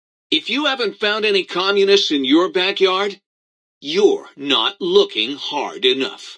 Category: Old World Blues audio dialogues Du kannst diese Datei nicht überschreiben.